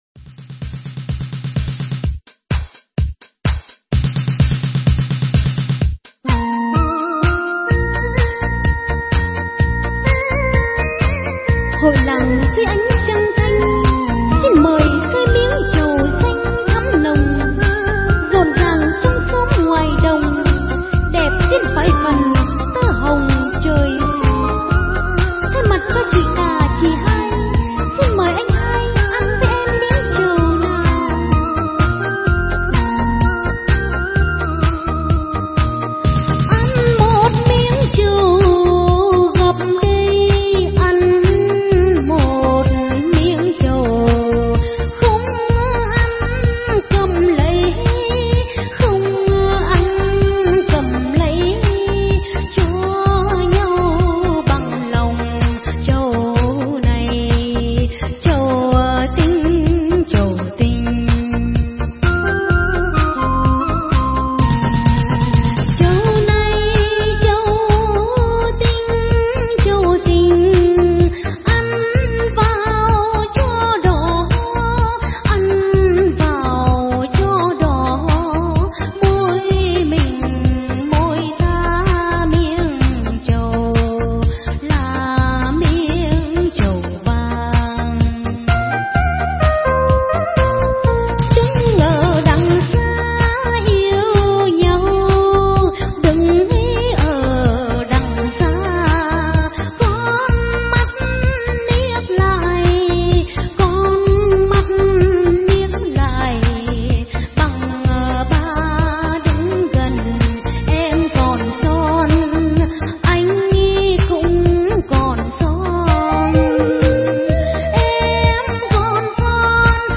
Dân Ca Quan Họ